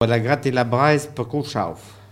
Notre-Dame-de-Monts
Locutions vernaculaires